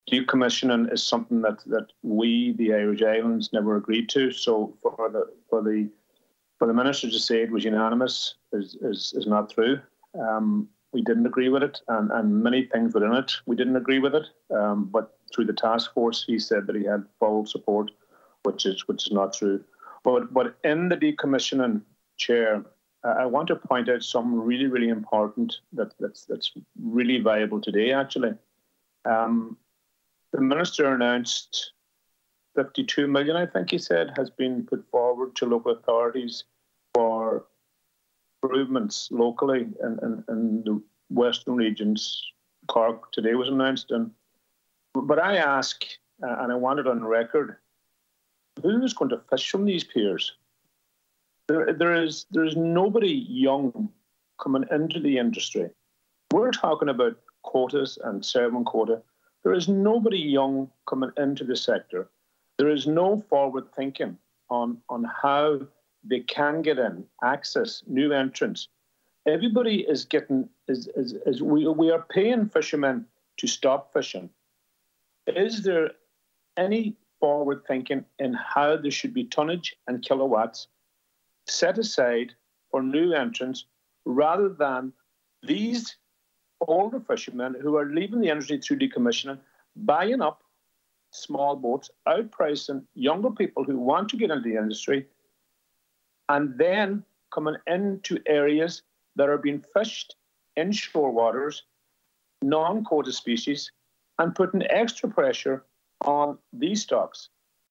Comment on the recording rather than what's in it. told the committee via Zoom that Minister Charlie McConalogue is announcing lots of money for piers